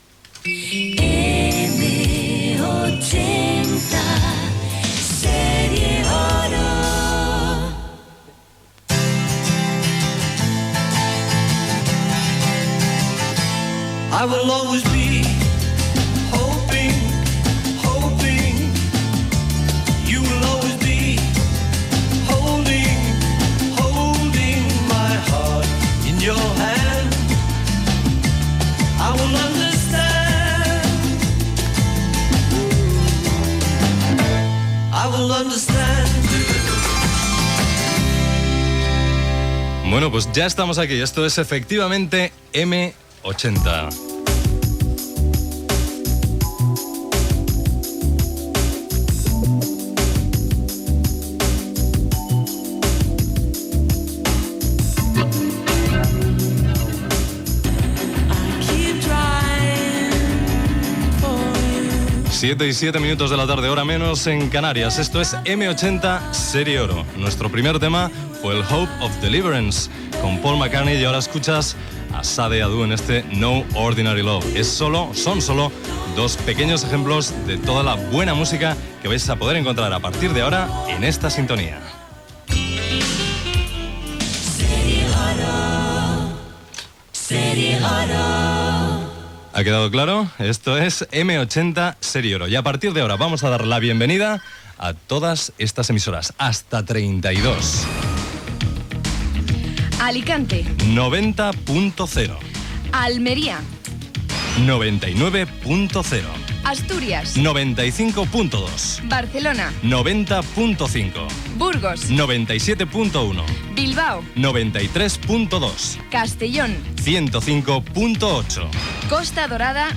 Emissió inaugural de la cadena, després que acabés Radio 80 Serie Oro.
Indicatiu de l'emissora, tema musical, hora i benvinguda a M80.